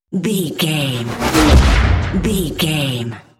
Dramatic chopper to hit 652
Sound Effects
Atonal
dark
futuristic
intense
tension
woosh to hit
the trailer effect